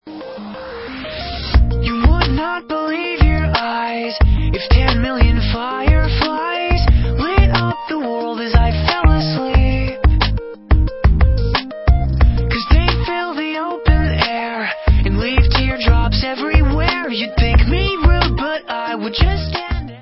disco, evropský elektropop a osmdesátkový synthpop
Čerstvý, zasněný pop pro každého!